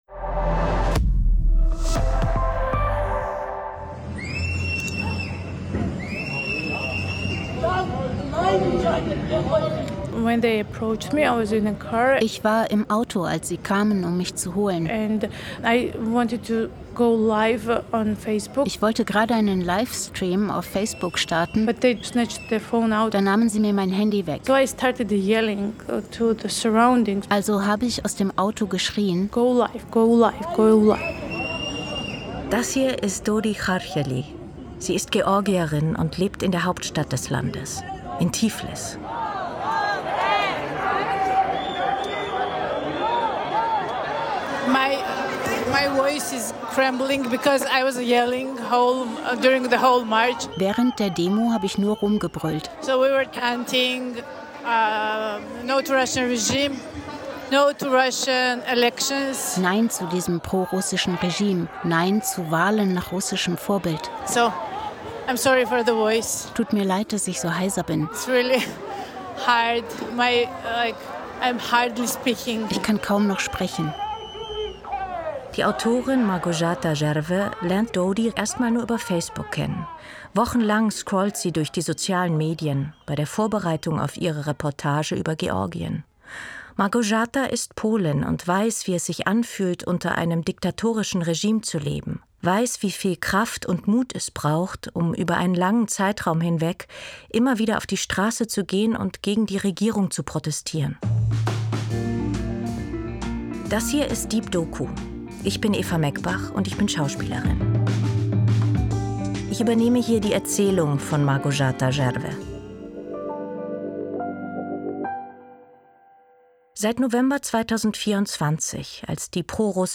Deep Doku erzählt persönliche Geschichten und taucht alle zwei Wochen in eine andere Lebensrealität ein. Egal ob im Technoclub, der Notrufzentrale der Feuerwehr, auf einer Demo oder im Wohnzimmer – wir sind in unseren Audio-Dokus und Reportagen ganz nah dran.